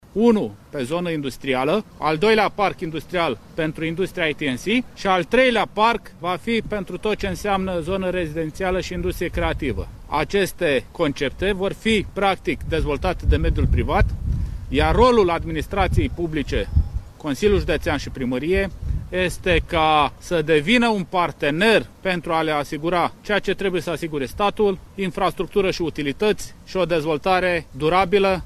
Preşedintele PNL Neamţ, Mugur Cozmanciuc, candidat la funcţia de preşedinte al Consiliului Judeţean Neamţ, a declarat, ieri, într-o conferinţă de presă, că va susţine dezvoltarea a trei parcuri industriale în judeţ.